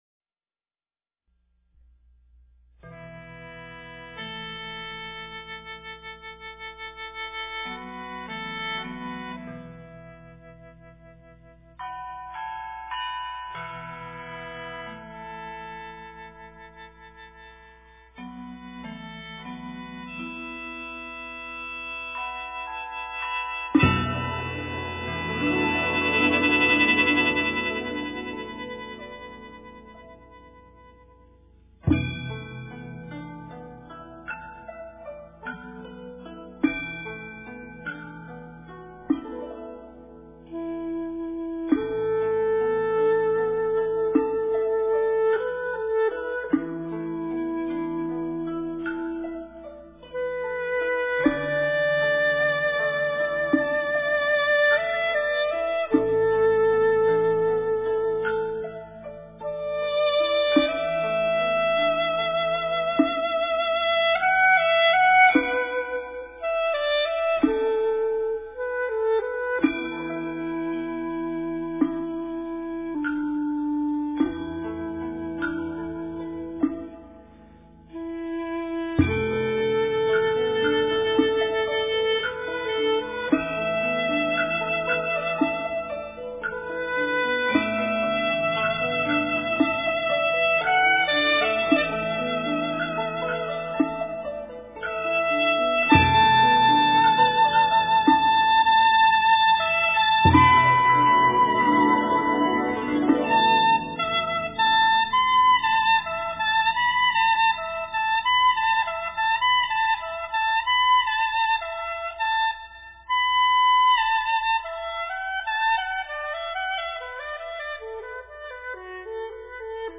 佛教音乐